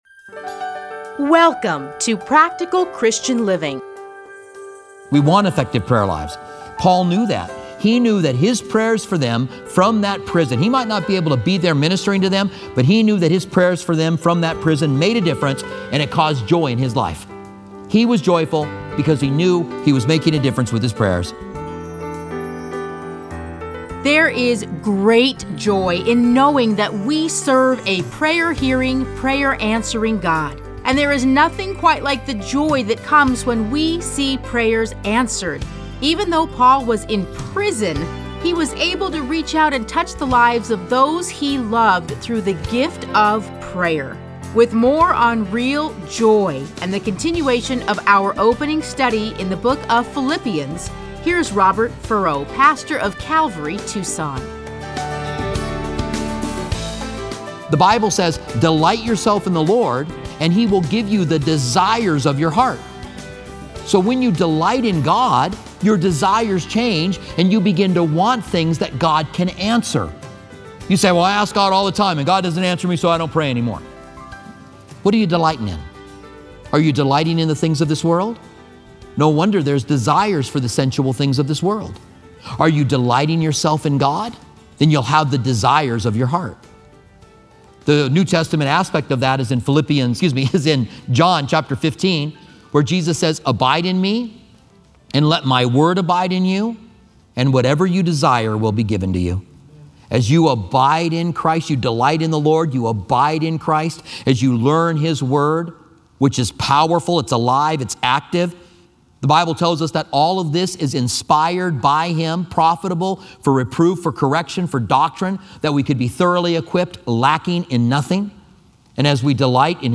Listen to a teaching from A Study in Philippians 1:1-30.